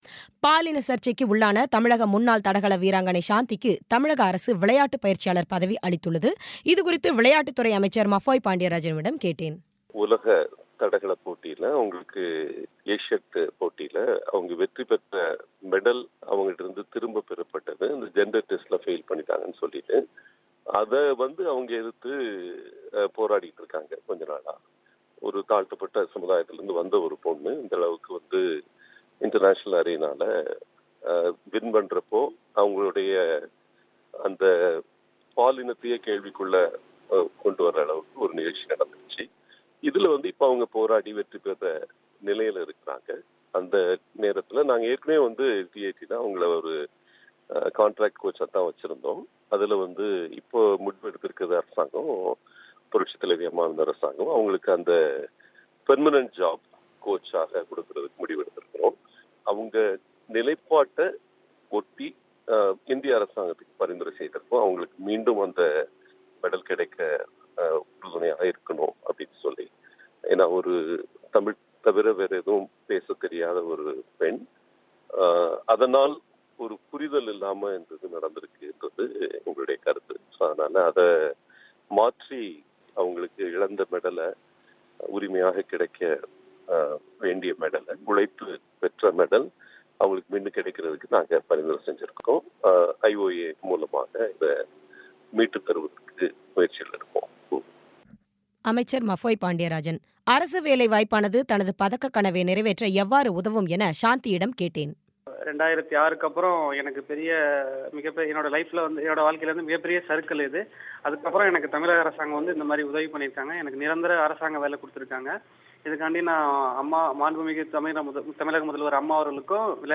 பாலின சர்ச்சையில் பதக்கம் பறிக்கப்பட்ட தடகள வீராங்கனை சாந்திக்கு அரசு வேலை கிடைத்துள்ளது குறித்த பேட்டி